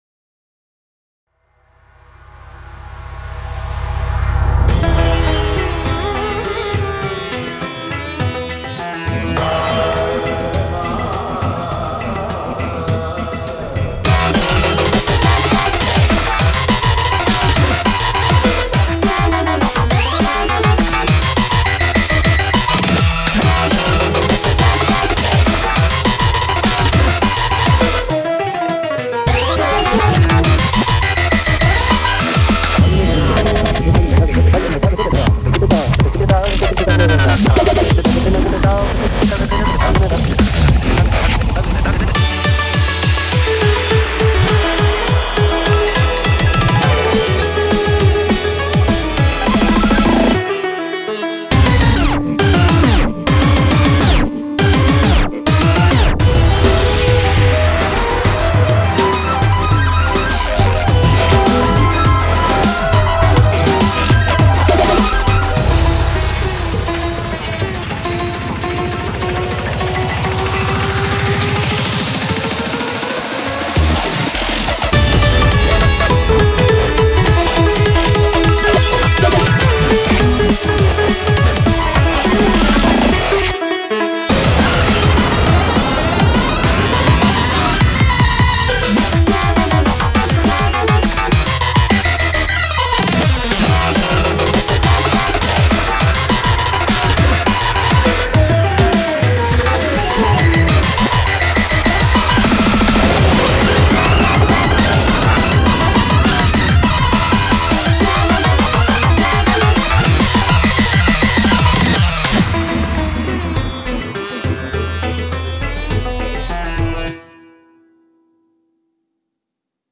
BPM103-205